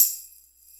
RS TAMBOURIN.wav